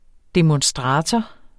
Udtale [ demɔnˈsdʁɑːtʌ ]